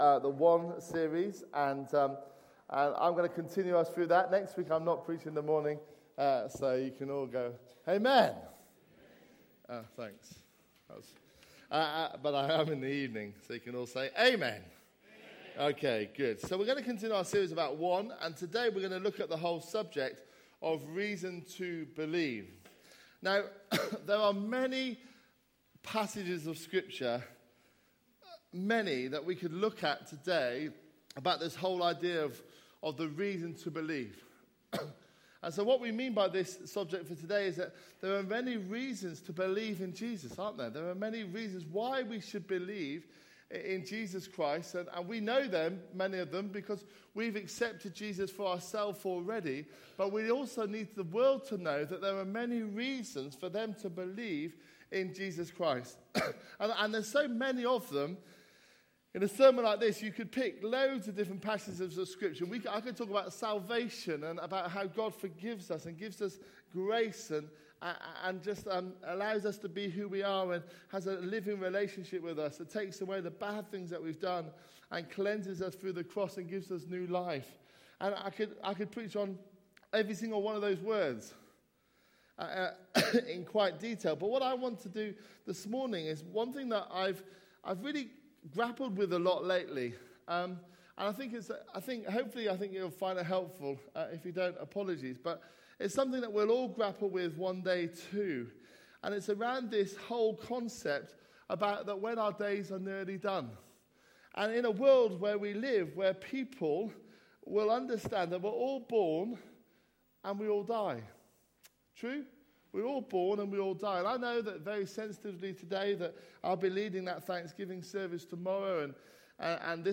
A message from the series "One."